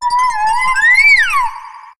Cri de Givrali dans Pokémon HOME.